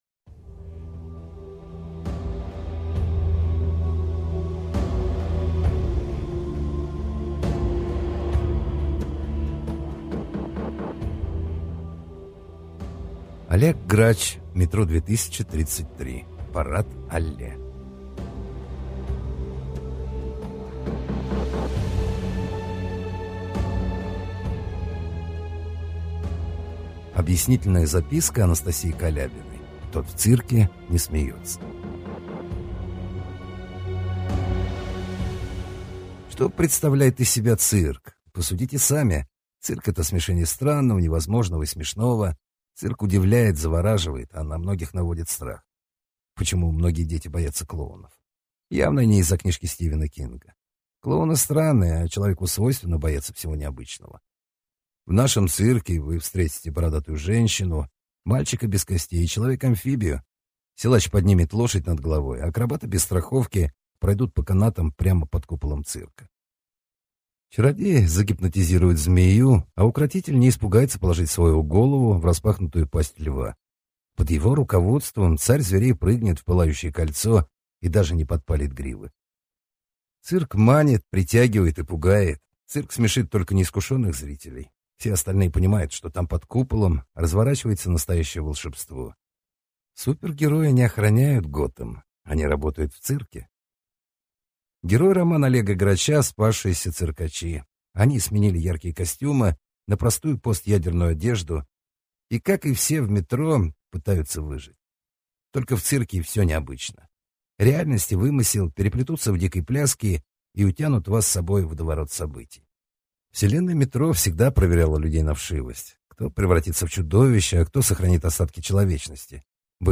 Аудиокнига Метро 2033: Парад-алле | Библиотека аудиокниг